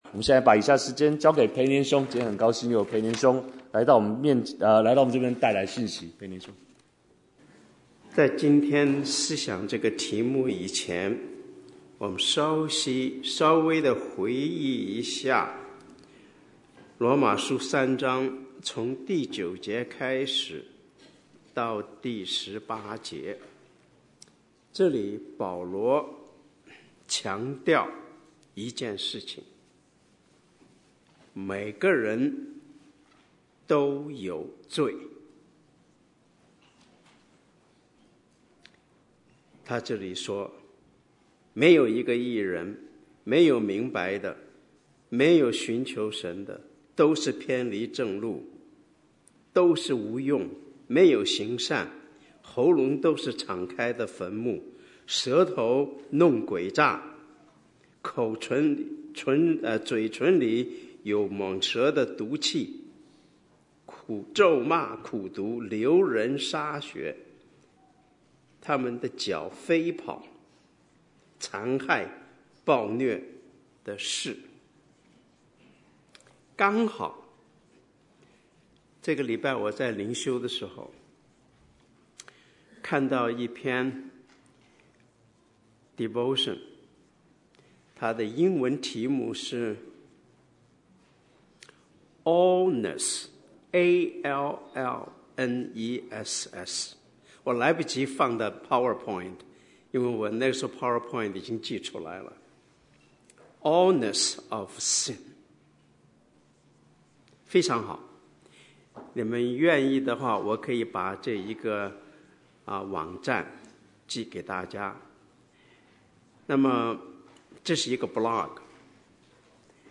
Bible Text: 羅馬書3:19-31 | Preacher